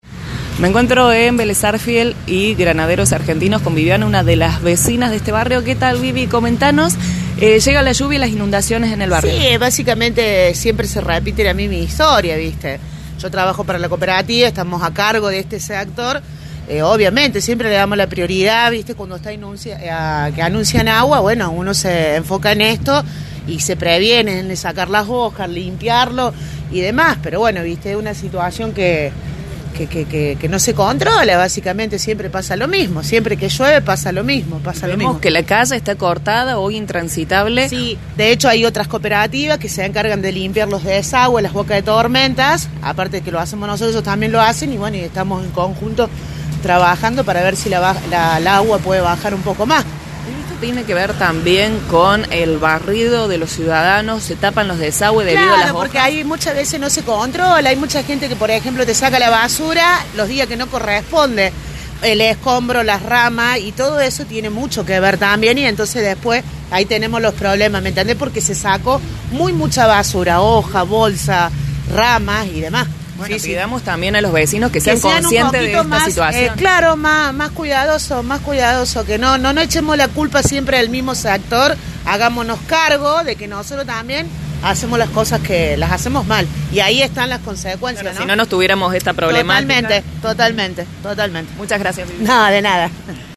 OTRO TESTIMONIO DE VECINOS